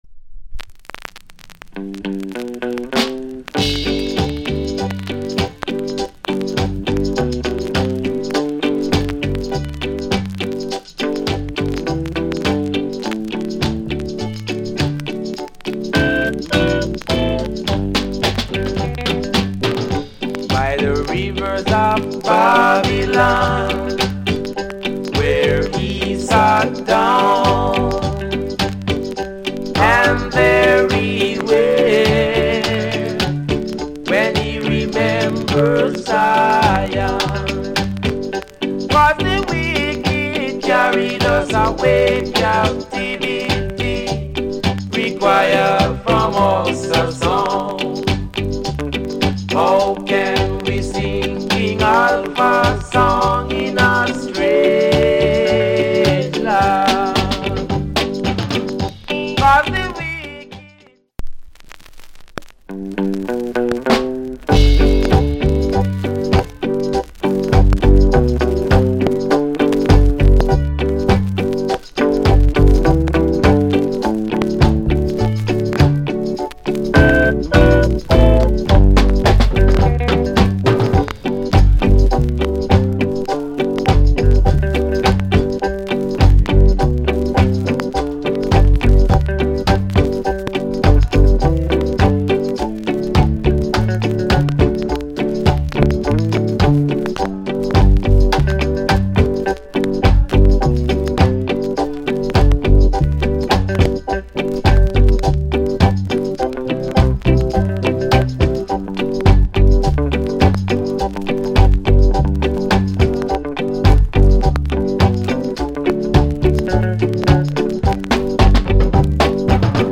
Early Reggae / Male Vocal Group Vocal Condition VG Soundclip